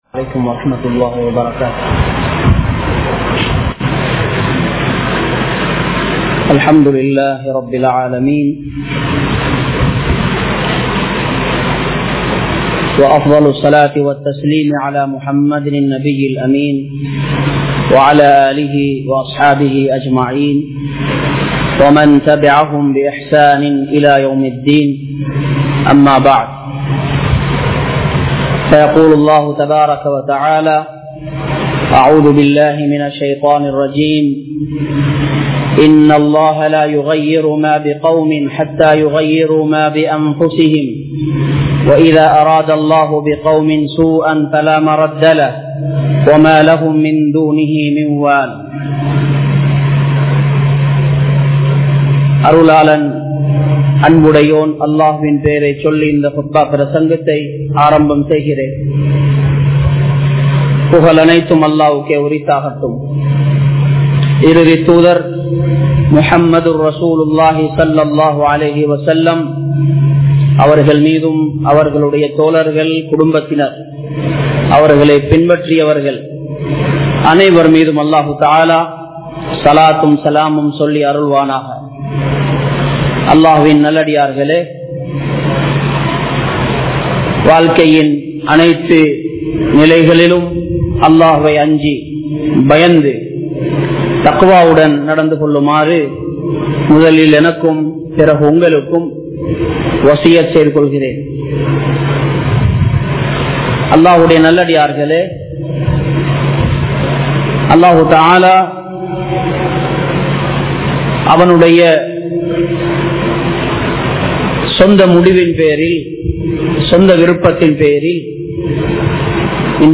Soathanaien Poathu Enna Seiya Veandum | Audio Bayans | All Ceylon Muslim Youth Community | Addalaichenai
Majma Ul Khairah Jumua Masjith (Nimal Road)